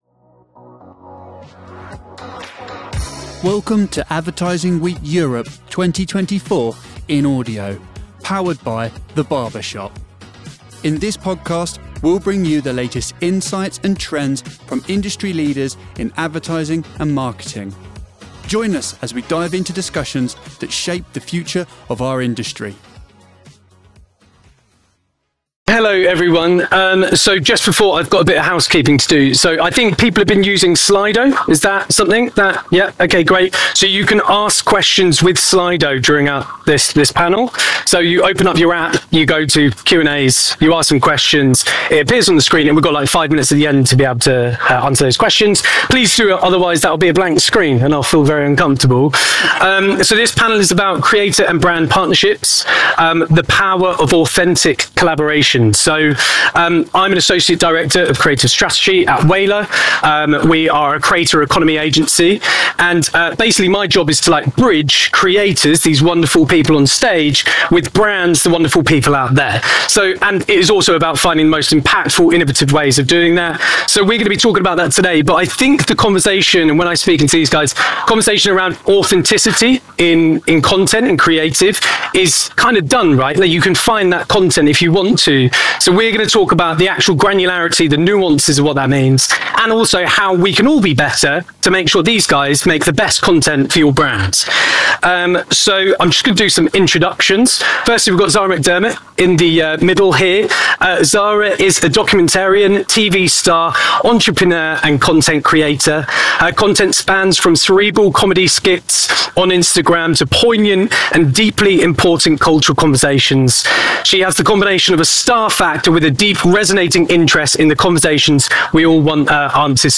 Learn about the strategies for building and maintaining authentic relationships with creators, ensuring alignment with brand values, and leveraging creator influence to reach wider audiences. This session provides actionable insights into creating impactful and resonant campaigns through authentic partnerships.